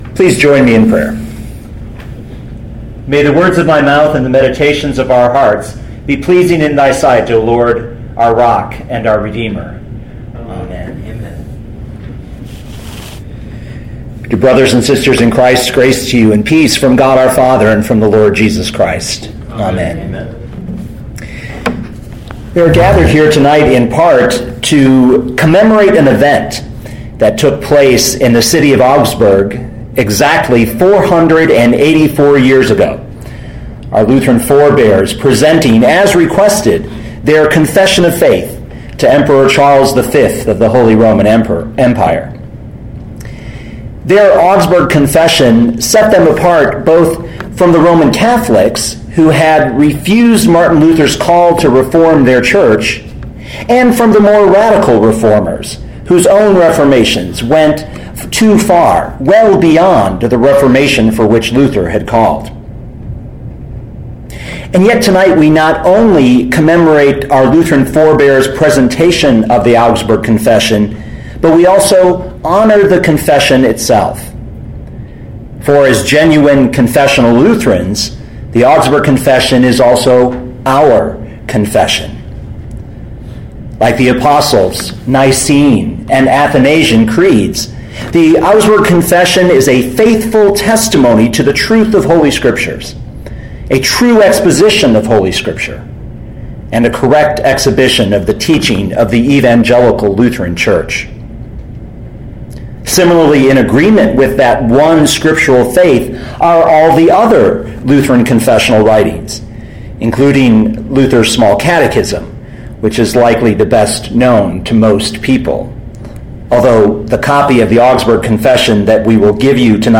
2014 John 15:1-11 Listen to the sermon with the player below, or, download the audio.